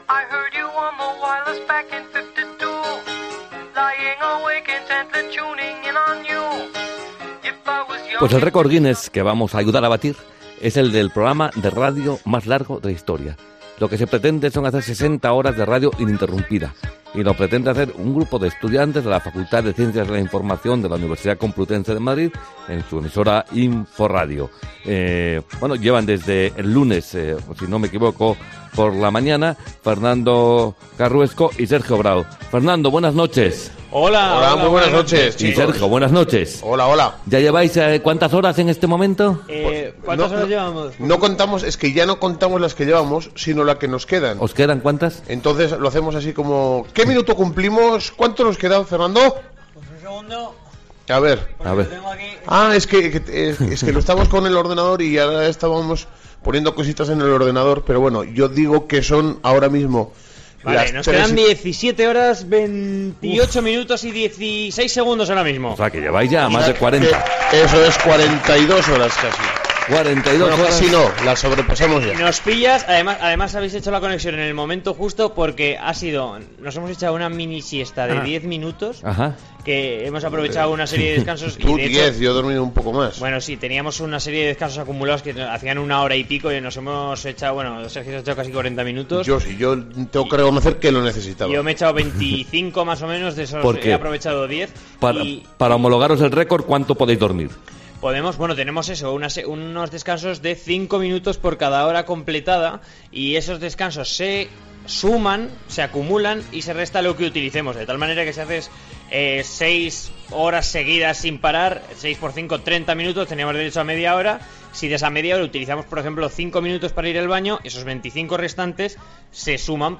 Por supuesto COPE no se perdió la cita y, mediante conexión, estuvo presente en él, teniendo la oportunidad los colaboradores que estaban ahí de aportar su granito de arena.